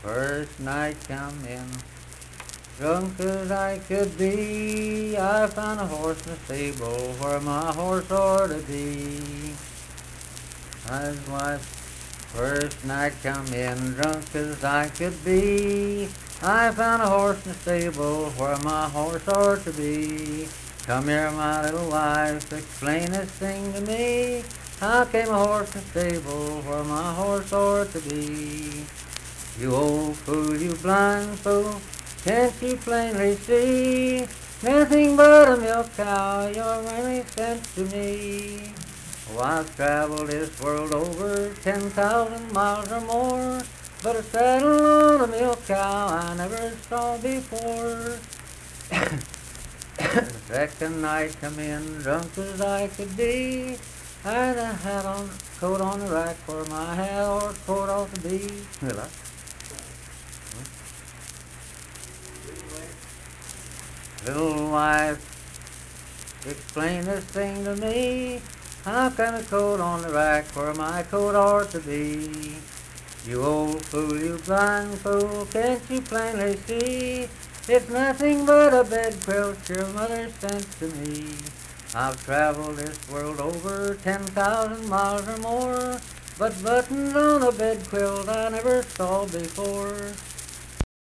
Unaccompanied vocal music performance
Verse-refrain 8(4).
Performed in Kliny, Pendleton County, WV.
Humor and Nonsense, Drinking and Drunkards, Marriage and Marital Relations, Bawdy Songs
Voice (sung)